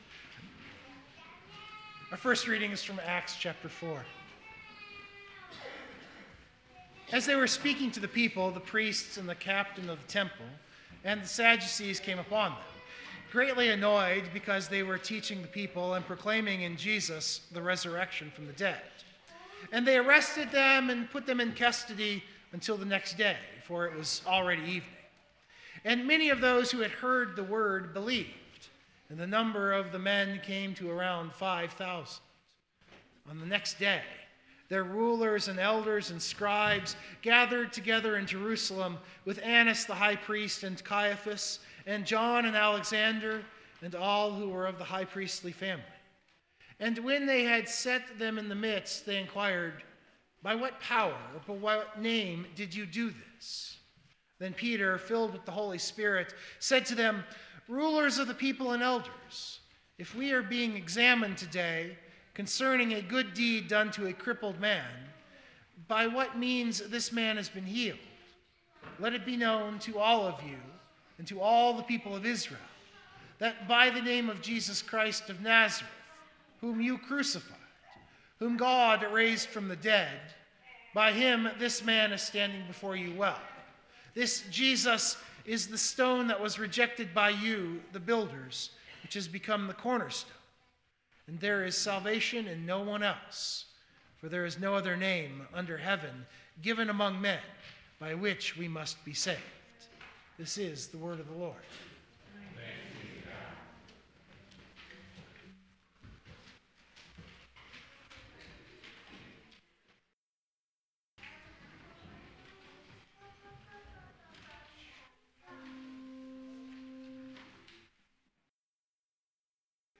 Biblical Text: Luke 3:15-22 Full Sermon Draft